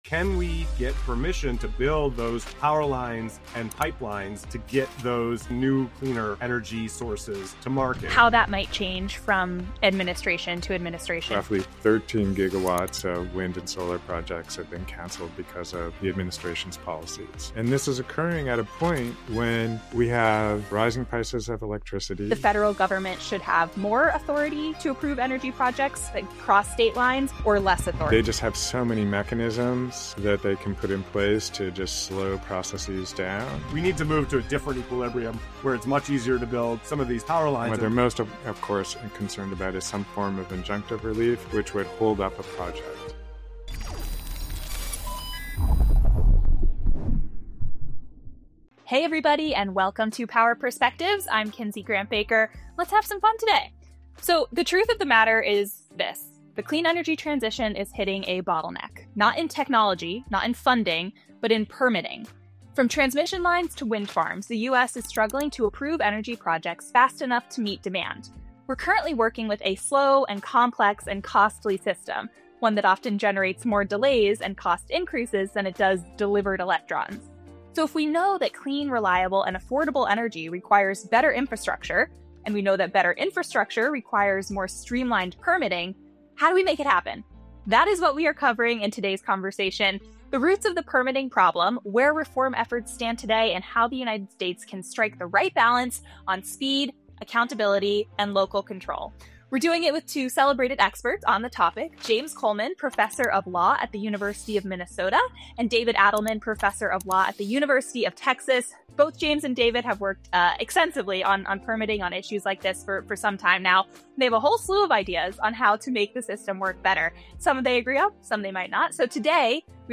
An energy permitting showdown: Two experts debate how fast is too fast